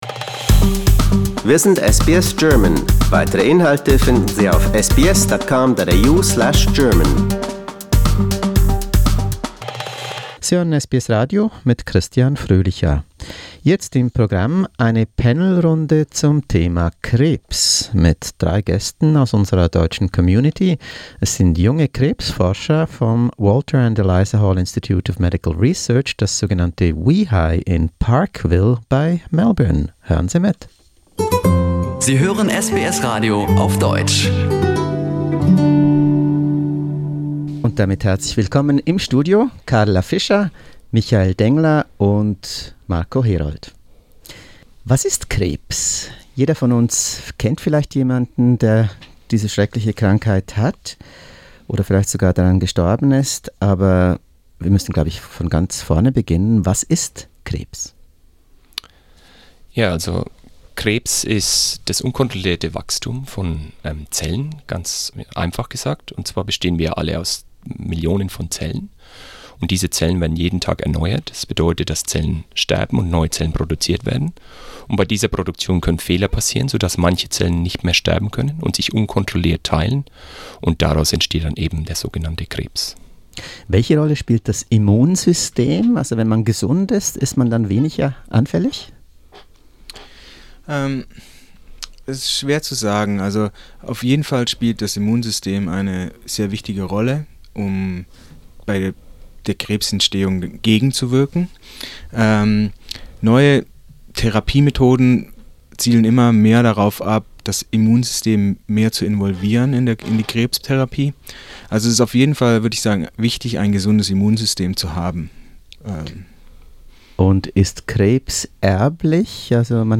And what are some of the new, promising therapies? Listen to an SBS panel discussion to find out more, from three young German cancer scientists at WEHI in Melbourne.